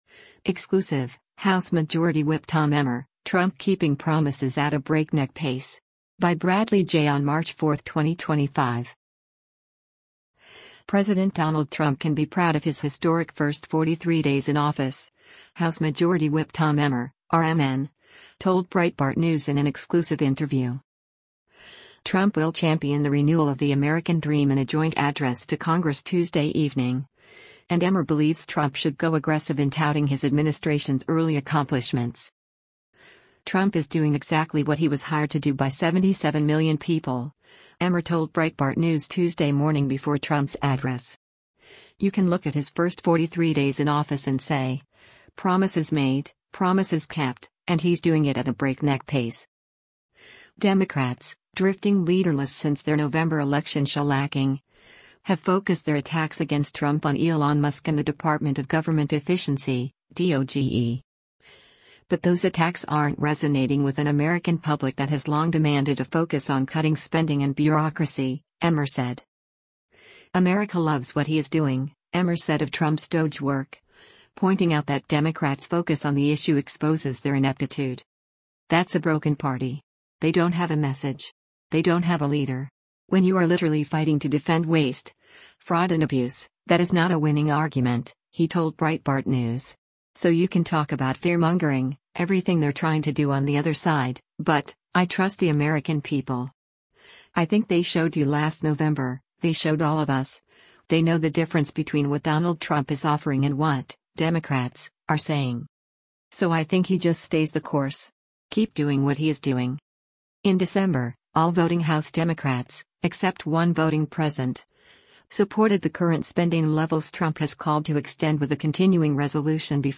President Donald Trump can be proud of his historic first 43 days in office, House Majority Whip Tom Emmer (R-MN) told Breitbart News in an exclusive interview.